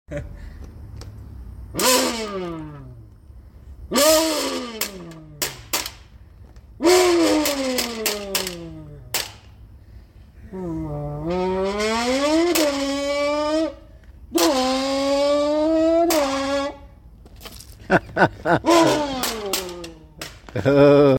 want your diesel to sound like an F1 car????! FREE MONSTER CANS WITH EVERY EXHAUST THIS MONTH 😂😂😂😂😂😂😂😂😂😂😂 Want Your Diesel To Sound Sound Effects Free Download.